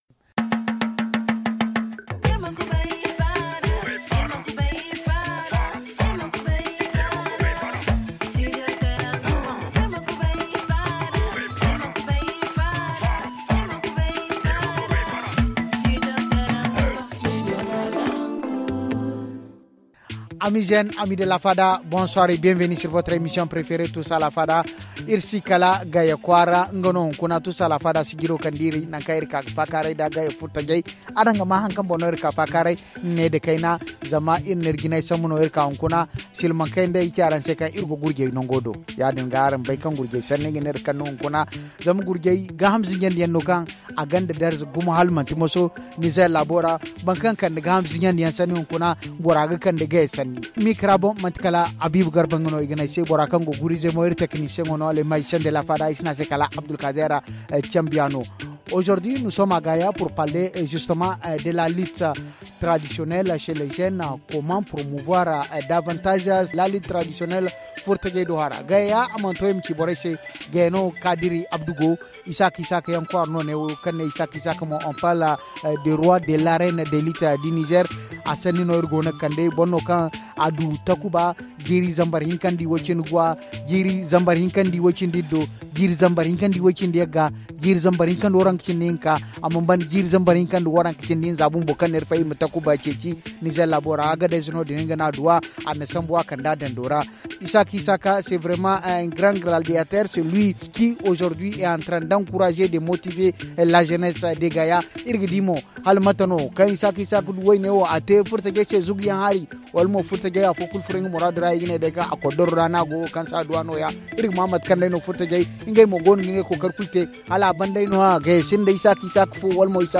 Aujourd’hui, la Fada s’est délocalisée de Niamey, la capitale, pour la région de Dosso, précisément dans le département de Gaya.